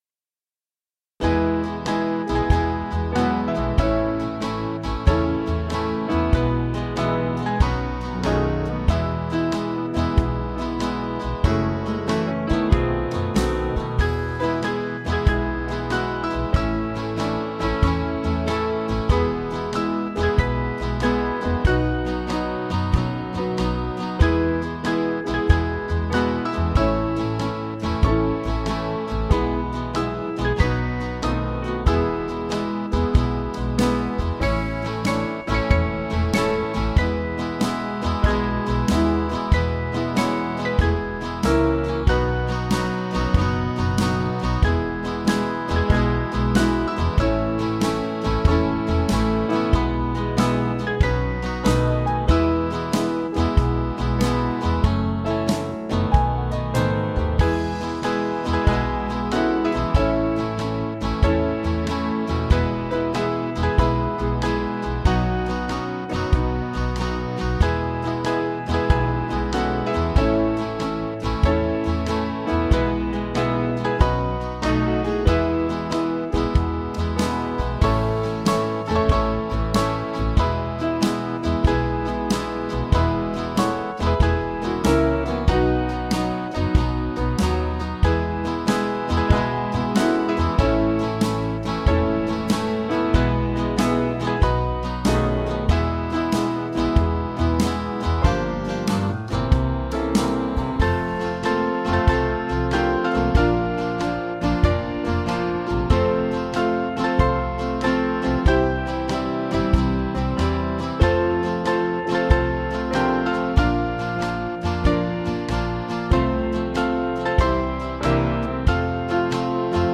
Country/Gospel